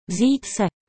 Besides, our audio records with translation, which were made by native speakers will help you to learn the right pronunciation of all these Czech verbs with no effort.